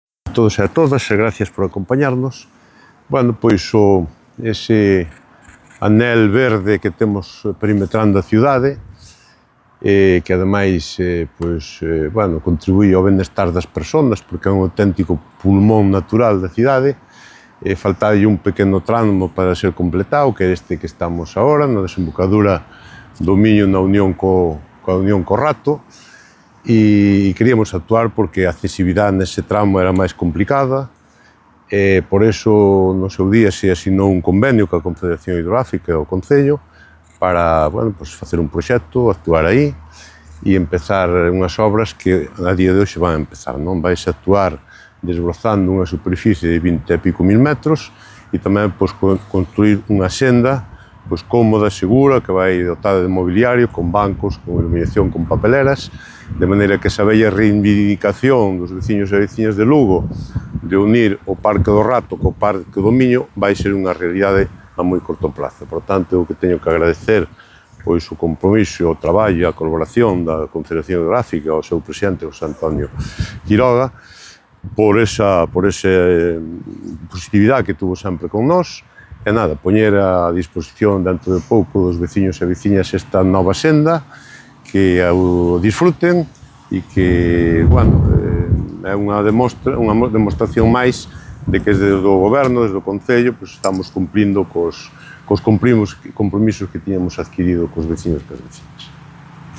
• El alcalde de Lugo, Miguel Fernández, sobre el paseo fluvial del Rato con el del Miño |